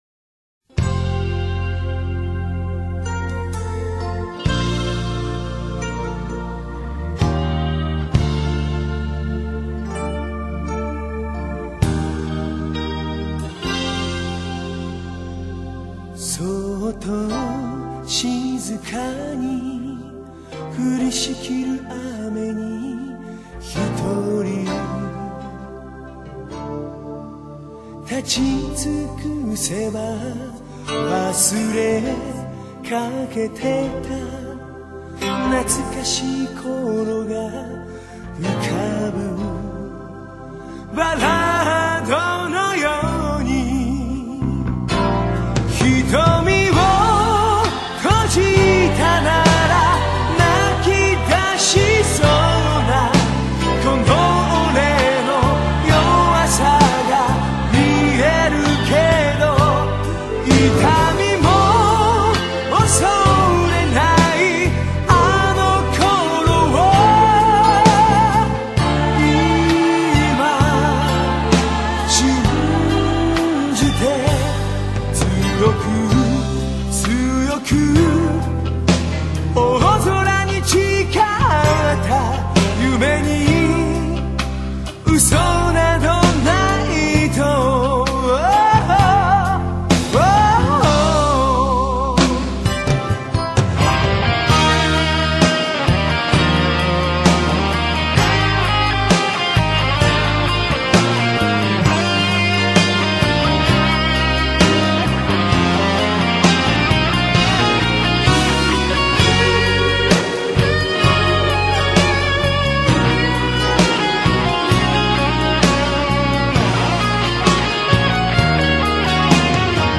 (오케스트라 사운드가 거의 사용되지 않았으며 기계적인 사운드에만 의존.)